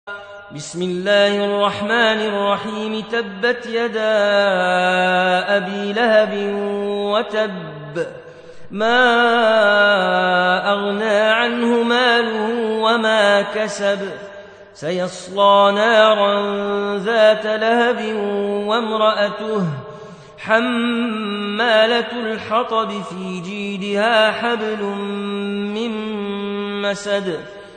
Audio Quran Tarteel Recitation
Surah Sequence تتابع السورة Download Surah حمّل السورة Reciting Murattalah Audio for 111. Surah Al-Masad سورة المسد N.B *Surah Includes Al-Basmalah Reciters Sequents تتابع التلاوات Reciters Repeats تكرار التلاوات